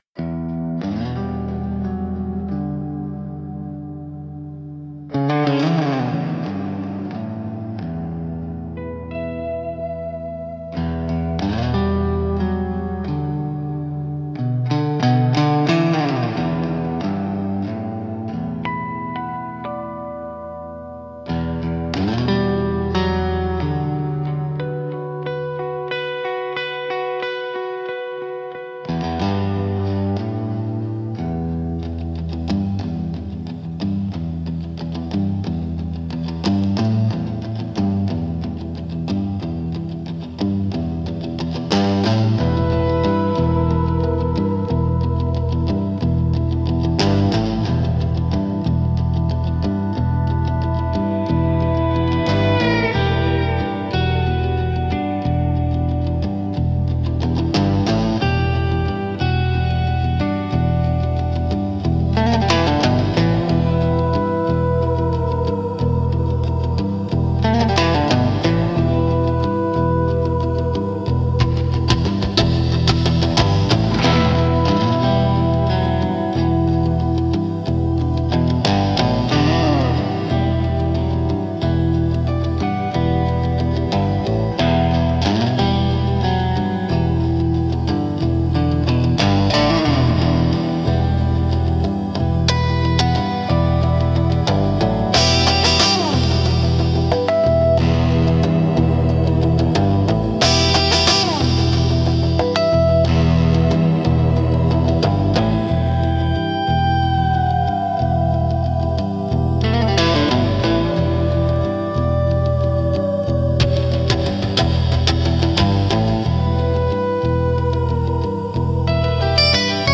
Instrumental - The Road Between Us 4.54